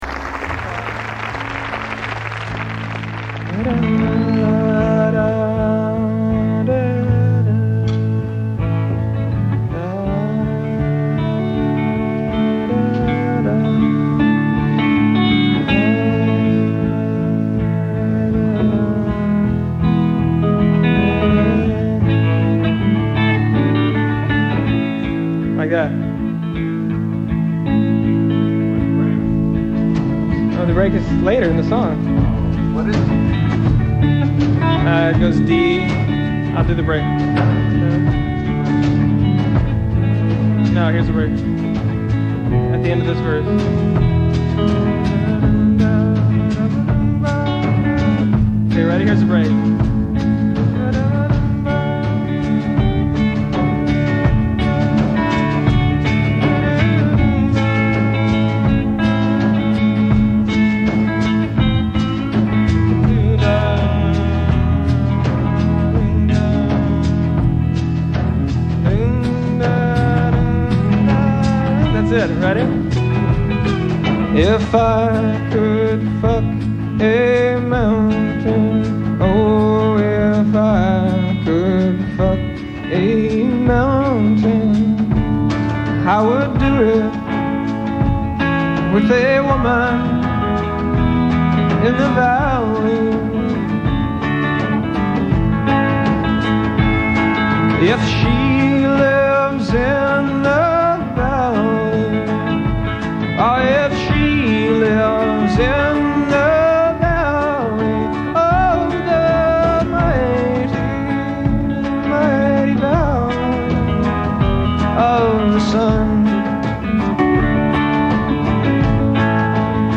enregistrée le 26/01/1999  au Studio 105